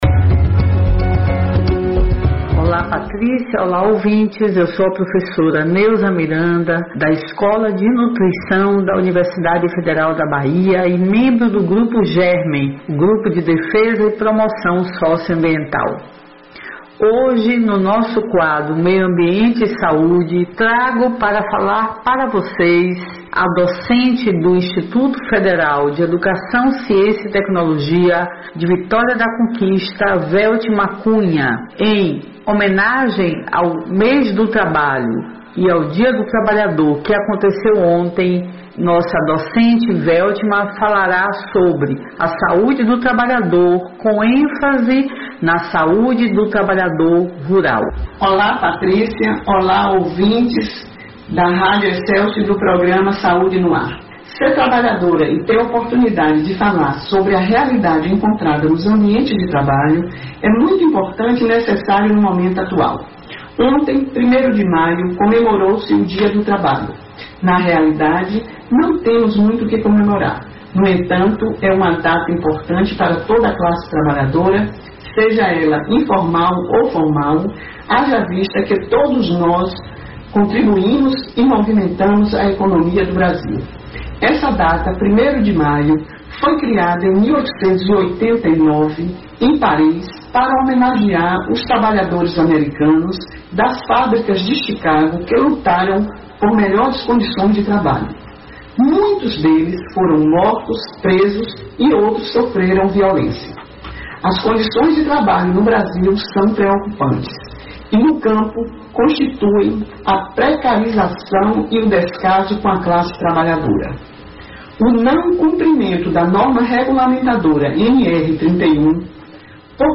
O assunto foi tema do quadro “Meio ambiente e Saúde”, veiculado às quartas-feiras pelo programa Saúde no Ar.